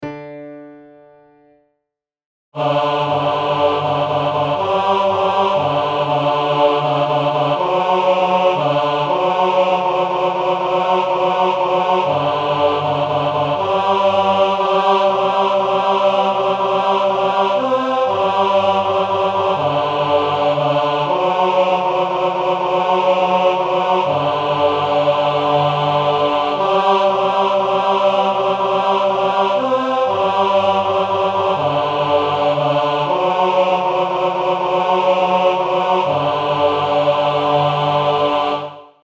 Bajazzo, Deutsches Volkslied
Tempo- und Lautstärken-Variationen sowie andere Ausdrucksvorgaben wurden nur wenig berücksichtigt.
BajazzoTenor.mp3